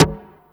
45 SD 2   -R.wav